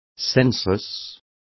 Also find out how sensual is pronounced correctly.